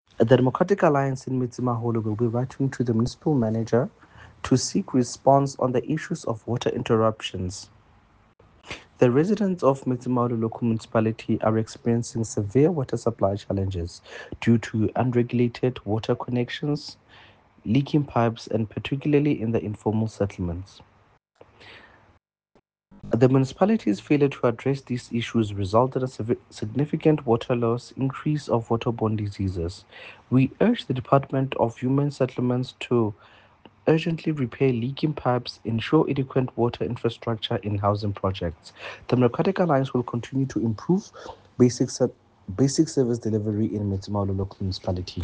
English soundbite by Cllr Teboho Thulo,